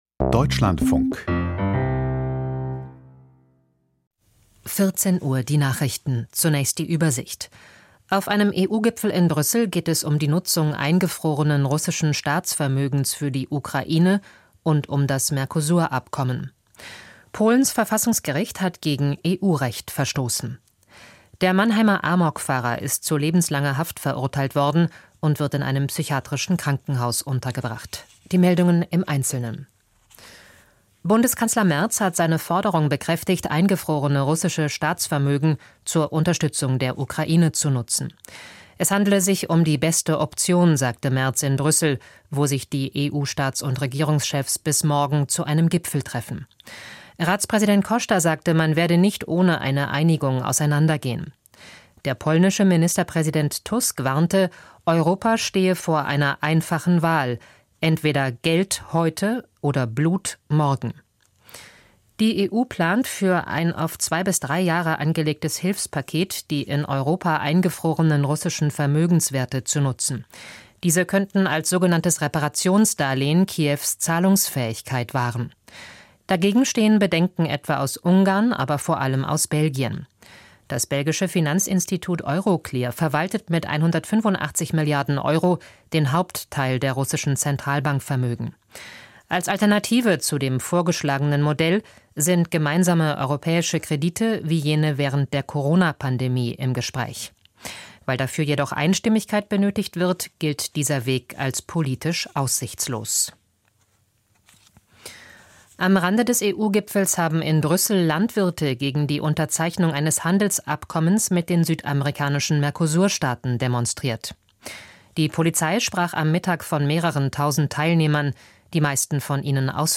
Die Nachrichten vom 18.12.2025, 14:00 Uhr